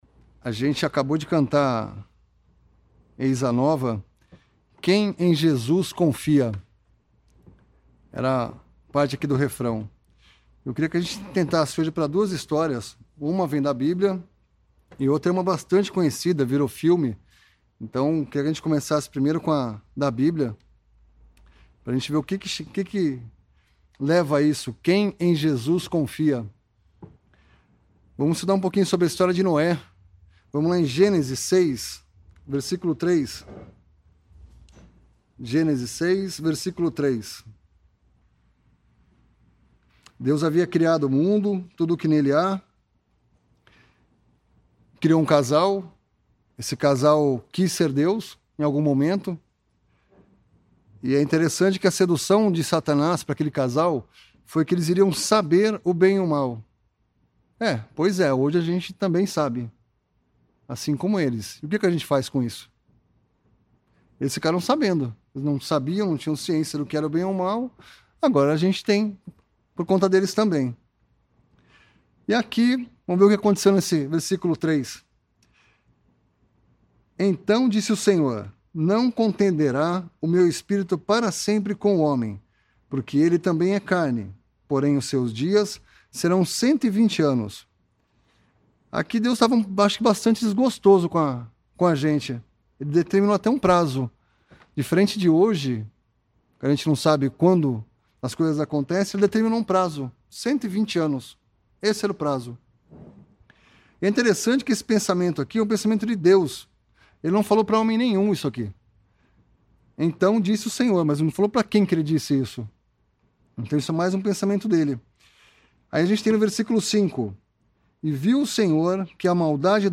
Arquivos em áudio com pregações do evangelho.